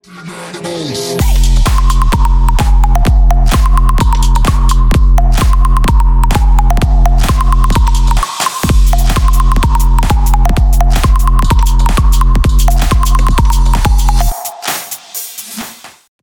Ремикс # без слов # клубные